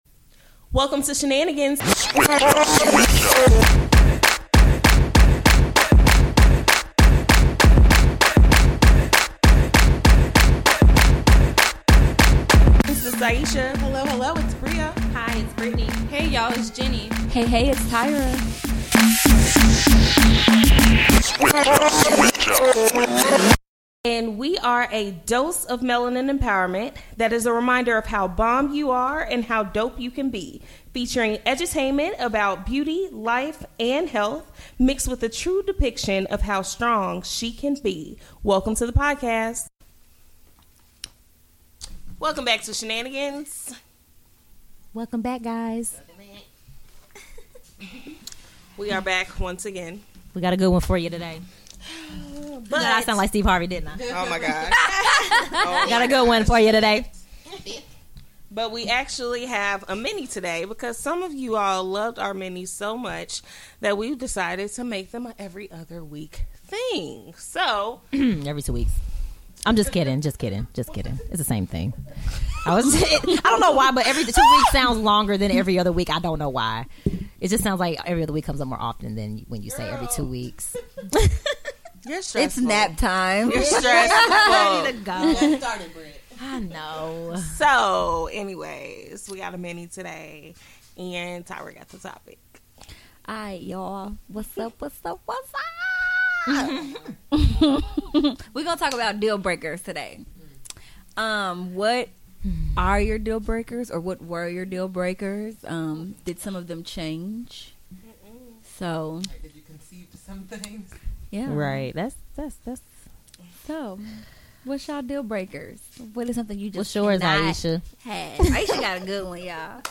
Listen to the SHE Team as we chat about what our deal breakers are. We are progressing through our 20’s and life is quickly hitting us, making our thoughts, needs and deal breakers ever evolve.